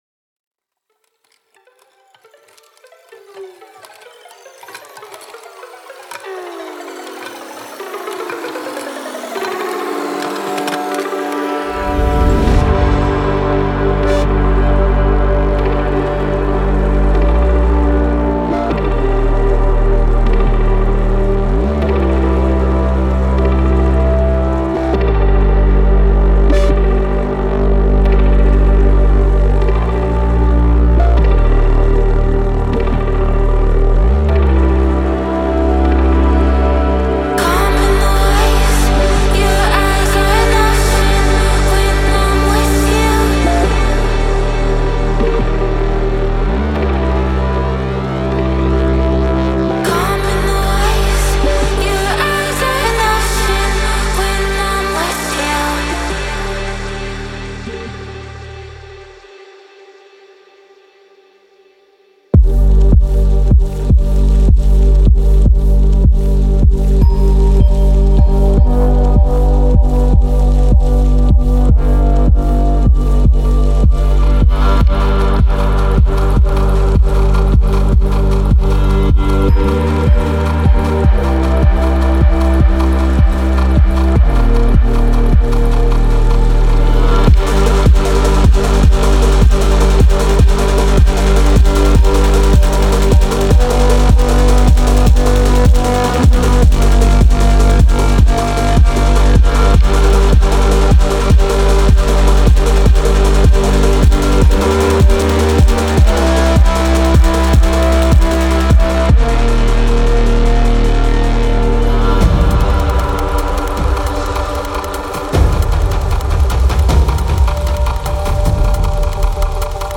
Cinematic Bass, Electronic, Experimental, Soundtrack
Instrumental